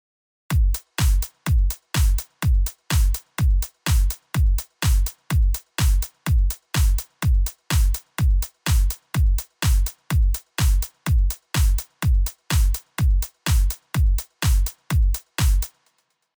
まずテスト用に用意した素のドラム。